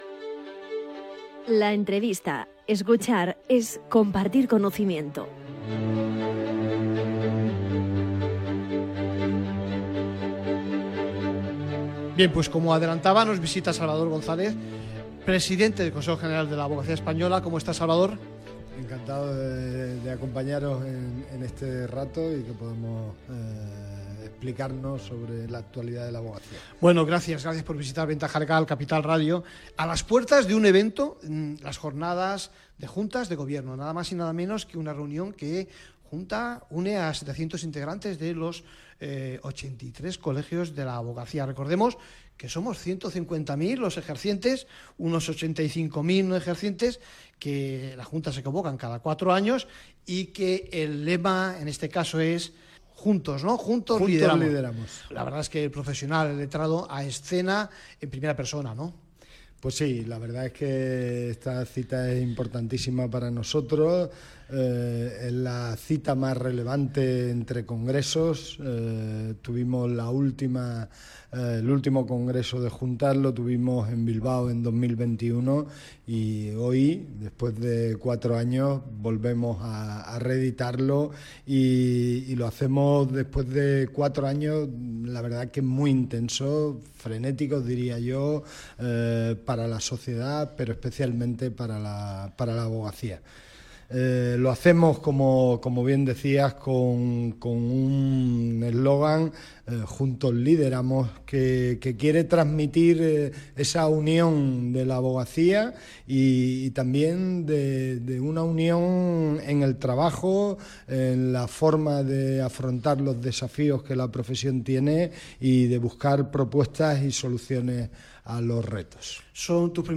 Actualidad Abogacía Entrevista a Salvador González, presidente de la Abogacía, en Ventaja Legal Jun 02 2025 | 00:14:42 1x 00:00 / 00:14:42 Subscribe Share Apple Podcasts Spotify Overcast RSS Feed Share Link Embed